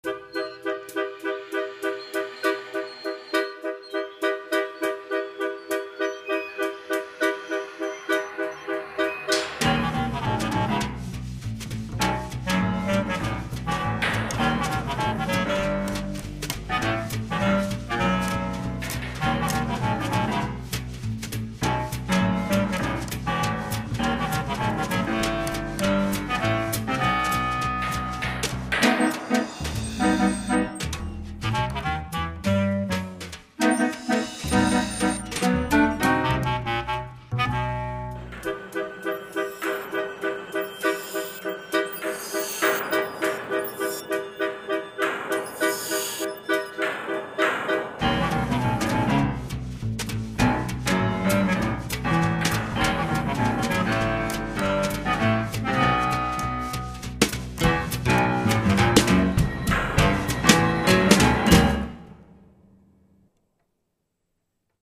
clarinet and bass clarinet
violin
cello
piano
guitar
double bass
drums, percussion and sampling